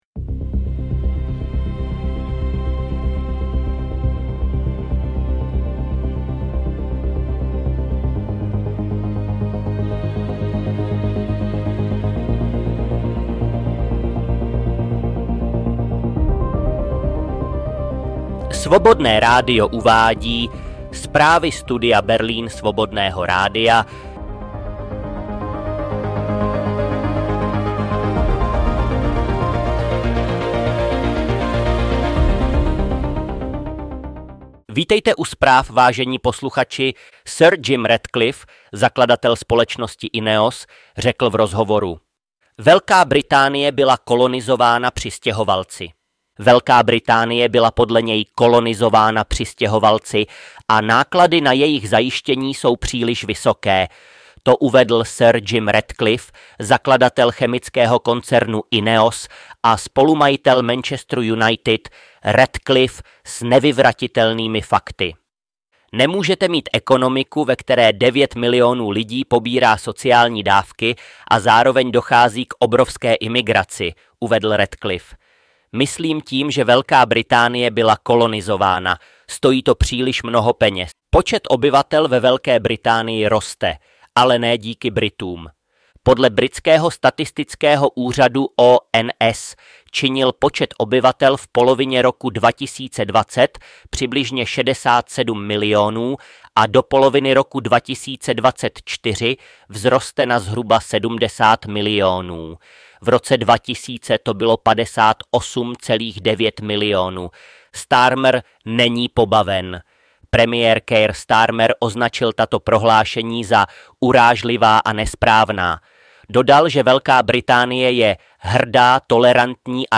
Hostem Studia Berlín je bezpečnostní analytik a bývalý ředitel Národního úřadu pro vyzbrojování MO ČR Ing. Jaroslav Štefec.